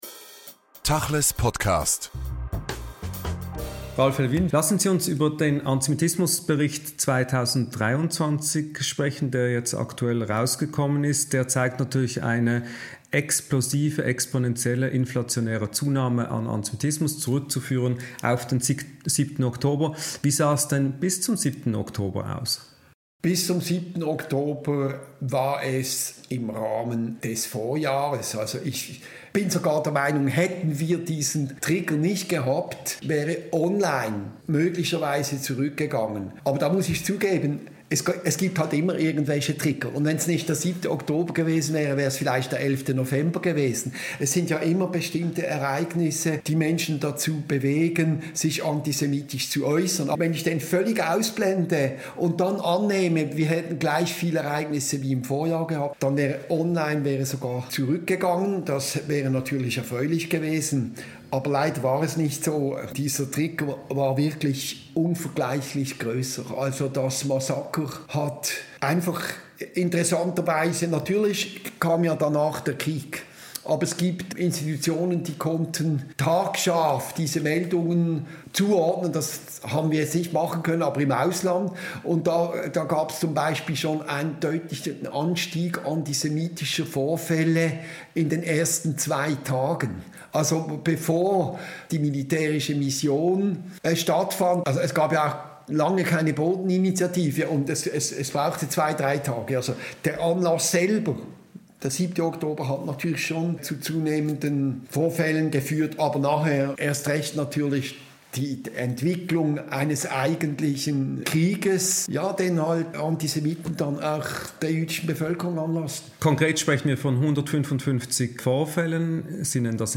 Am Dienstag publizierte der Schweizerische Israelitische Gemeindebund (SIG) mit der Stiftung gegen Rassismus und Antisemitismus (GRA) den Antisemitismusbericht 2023. Im tachles Podcast erklärt SIG-Präsident Ralph Lewin den massiven Anstieg von antisemitischen Attacken in der Schweiz und den Unterschied zu Vorjahren.